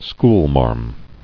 [school·marm]